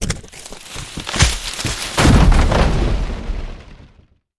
Wood Crash.wav